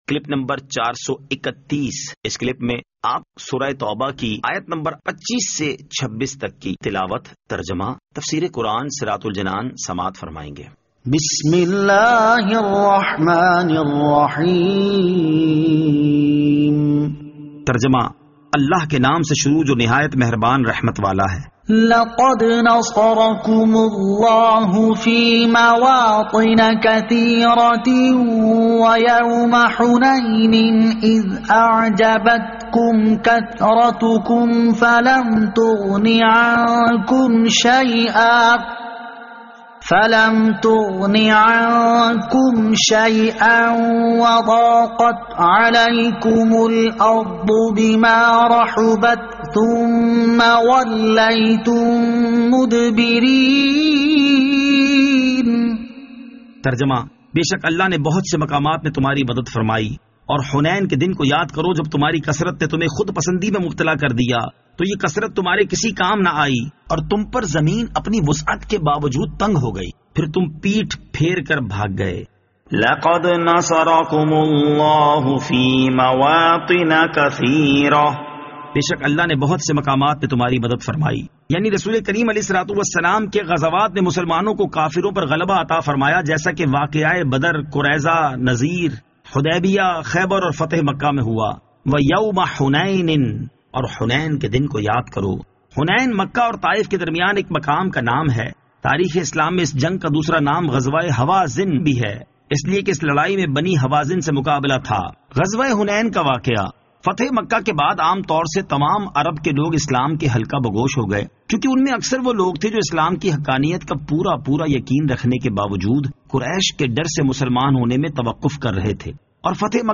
Surah At-Tawbah Ayat 25 To 26 Tilawat , Tarjama , Tafseer